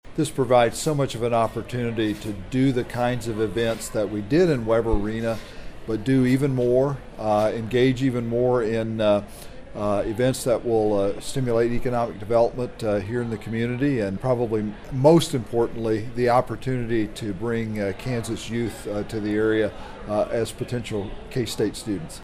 Friday’s ceremony was held inside the Stanley Stout Center, on the northern edge of the campus.